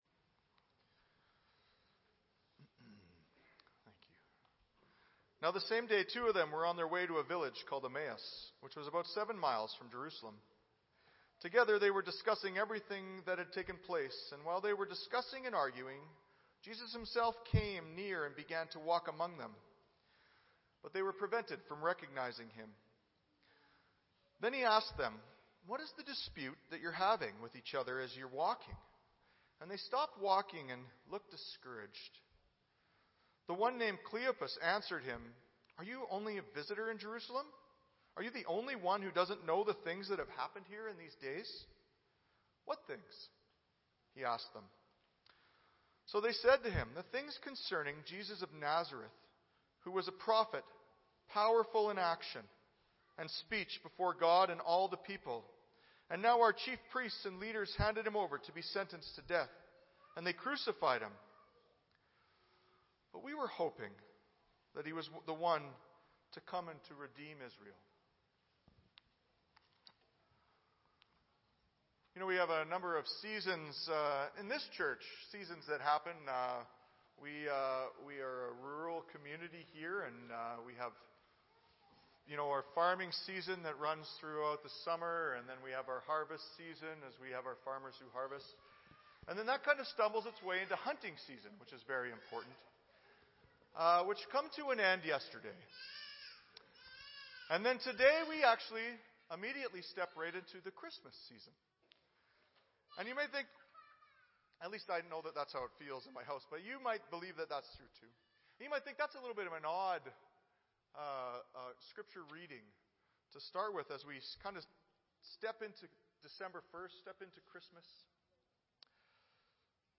Audio Sermon Library